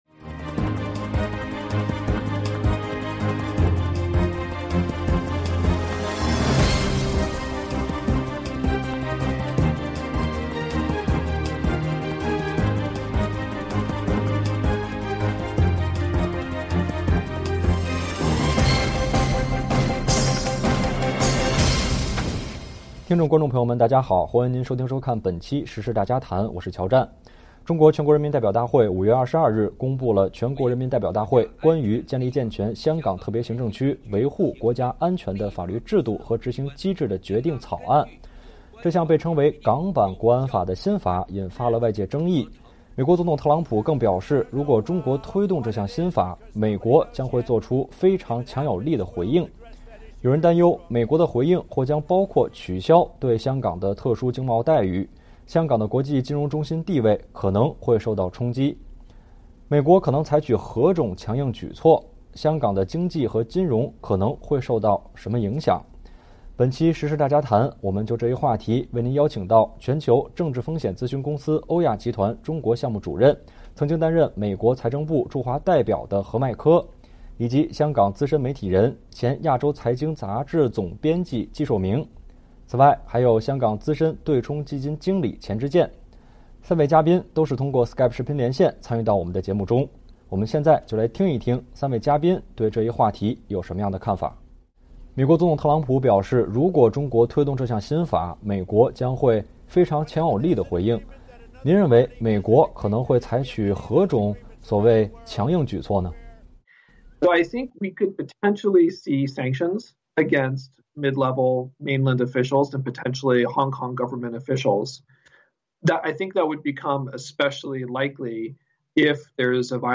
美国可能采取何种“强硬举措”？香港的经济和金融可能会受到什么影响？本期时事大家谈邀请三位嘉宾为您进行分析。